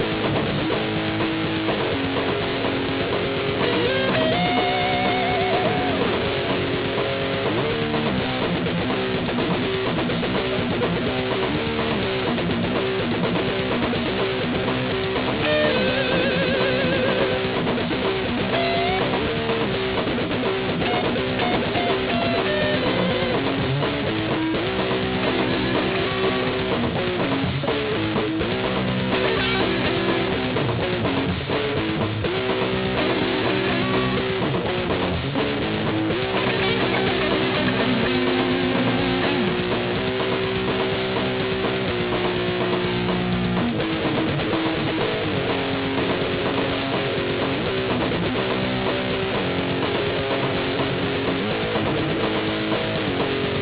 This is mostly a guitar and drum jam.